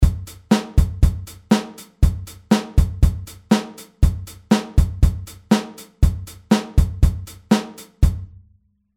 8telBeats01.mp3